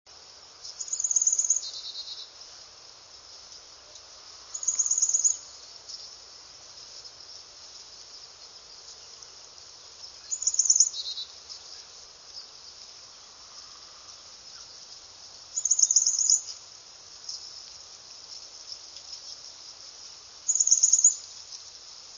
Carolina Chickadee
chickadee_car_variable_sees709.wav